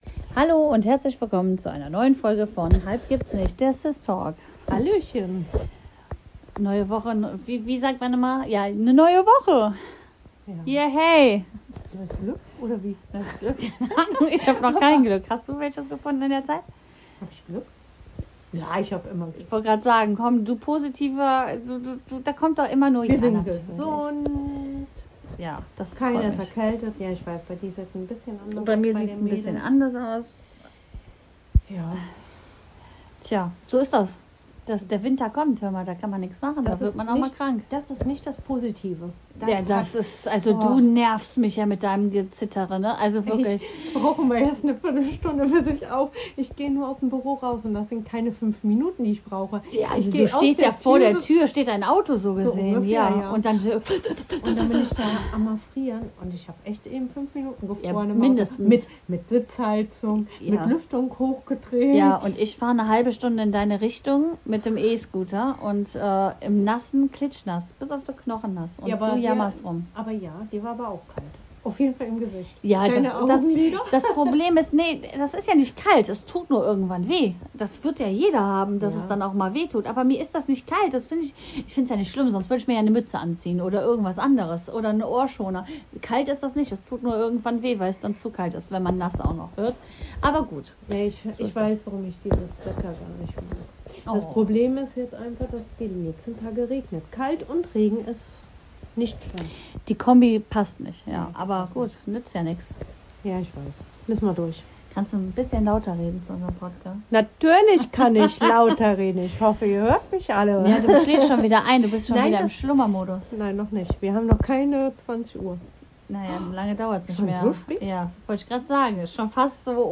In dieser Folge reden die zwei Schwestern darüber, wie Kinder mit Schule, Druck, Freundschaften und Gefühlen umgehen.